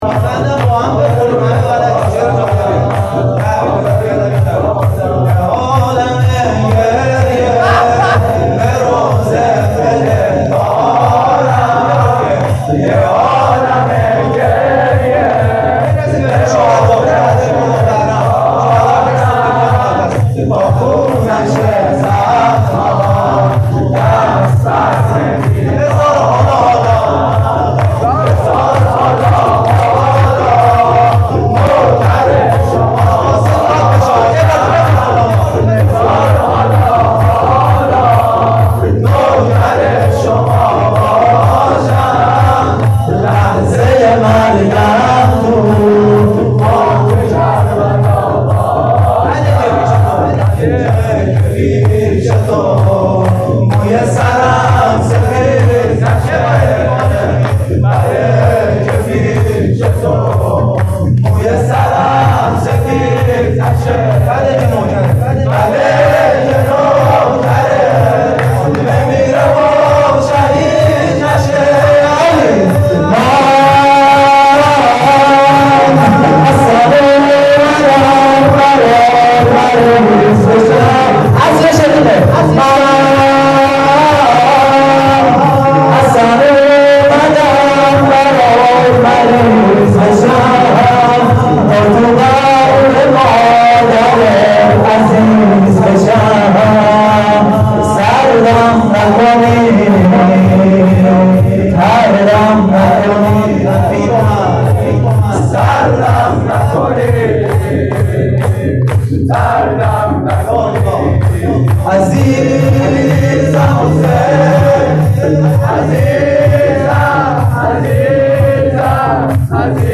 روضه جوانان مهدوی